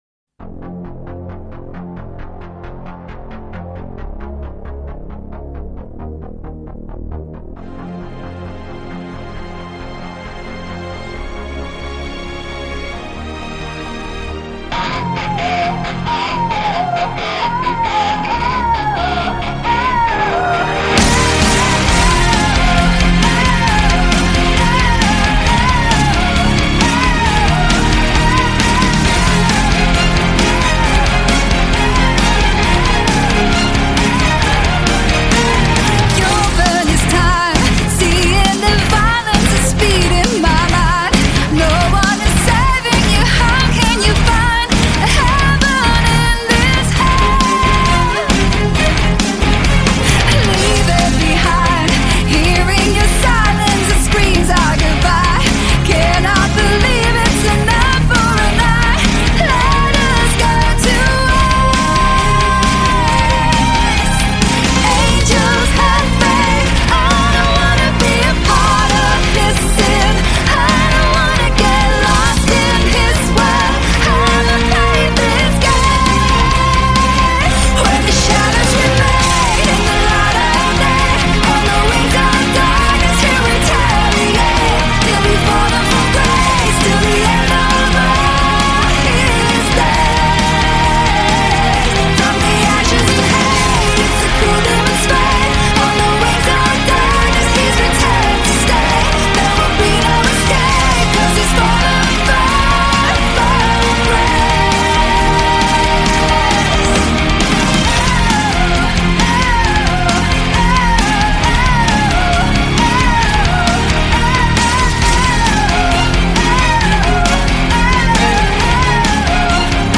Жанр: Hard Rock